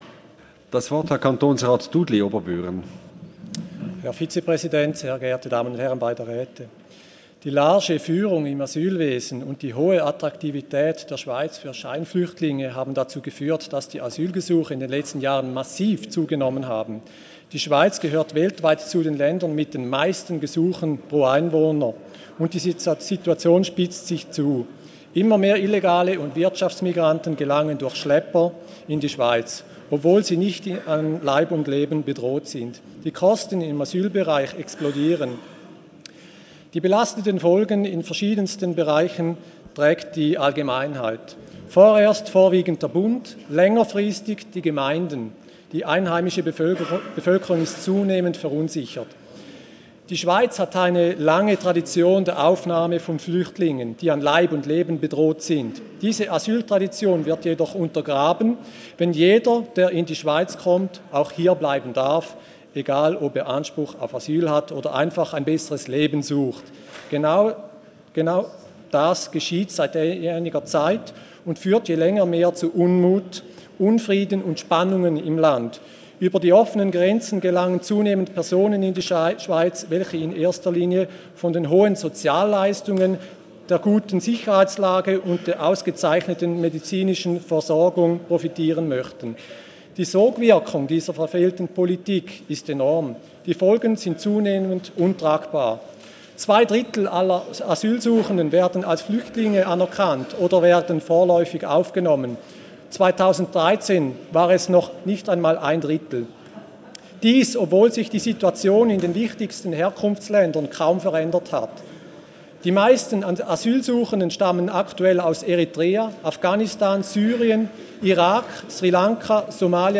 1.3.2016Wortmeldung
Session des Kantonsrates vom 29. Februar bis 2. März 2016, ausserordentliche Session vom 3. März 2016